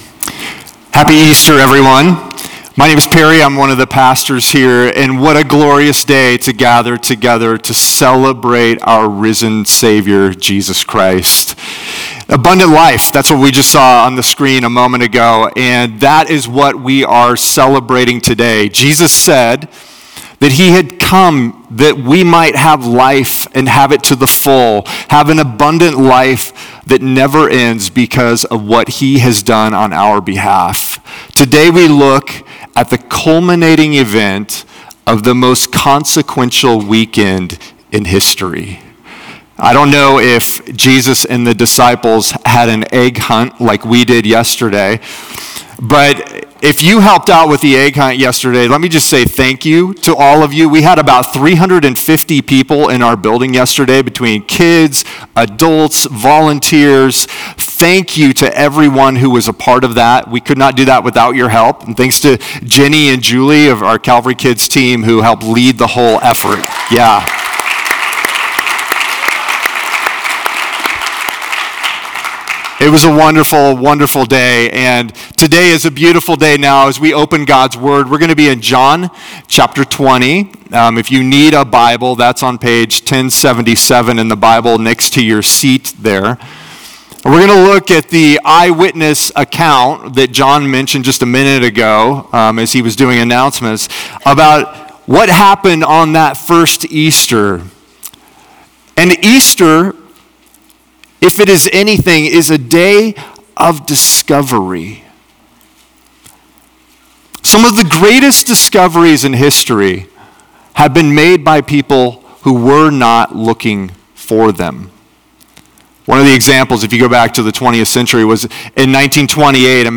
This sermon explores the significance of our opinion about Jesus and how it shapes our lives and faith. Journey with us through John 7 as we uncover the various perspectives on Jesus and learn to form a true understanding of His divine nature.